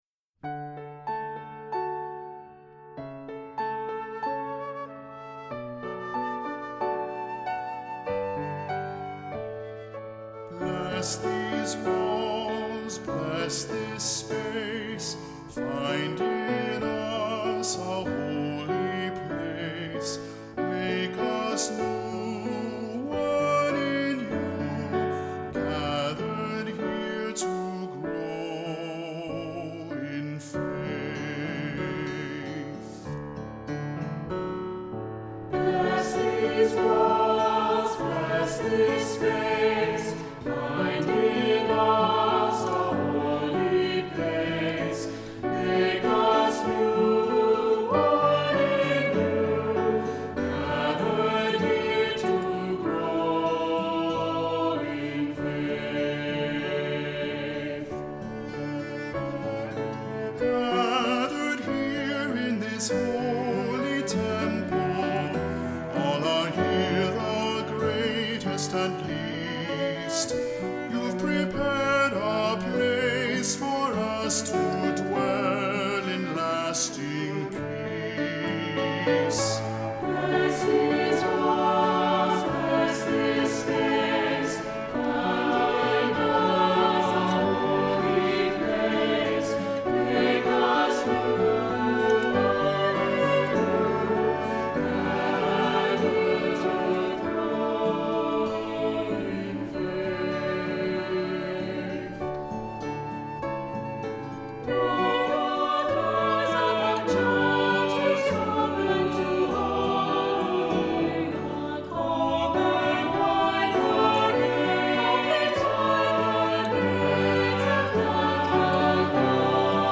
Accompaniment:      Keyboard
Music Category:      Christian
Cello or Bassoon.